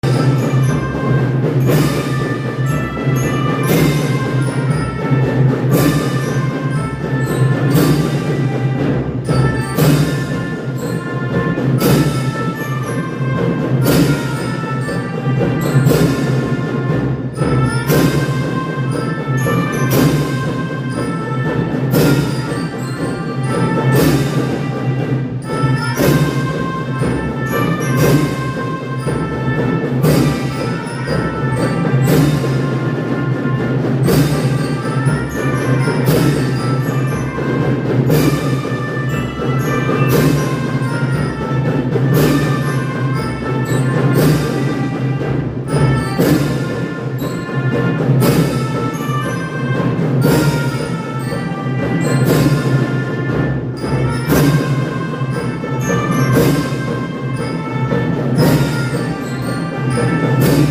全校を取り囲んで ６年生が鼓笛を演奏しました